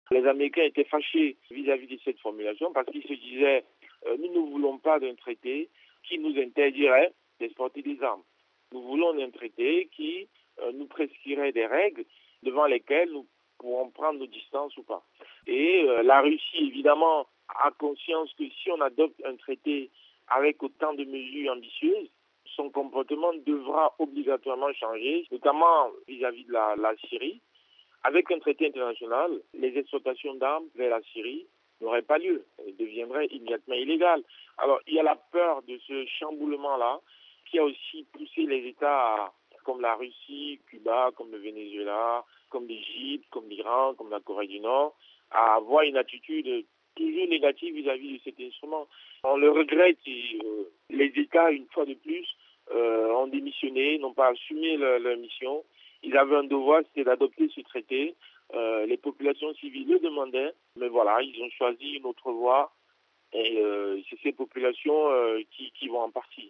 C’est d’ailleurs sur ce point précis que la Russie a bloqué la ratification du document, tout comme les Etats-Unis. Les explications